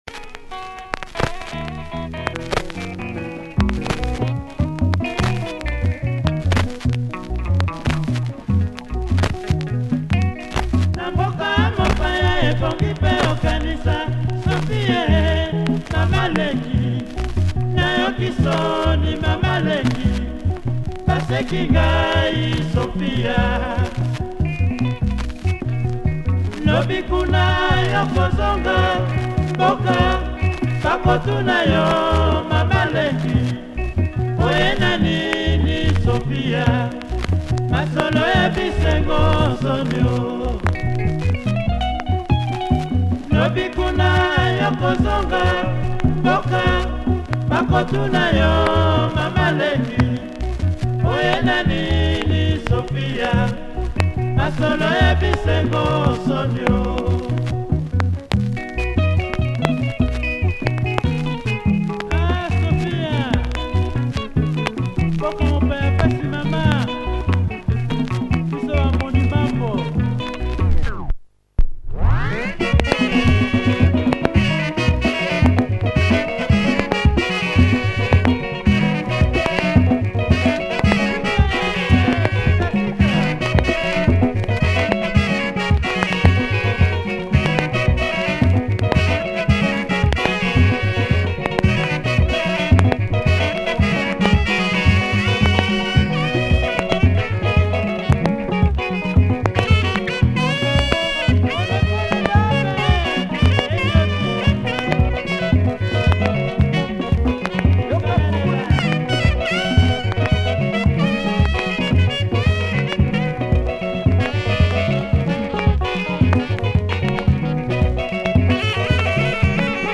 Nice Lingala
Quite funky in parts!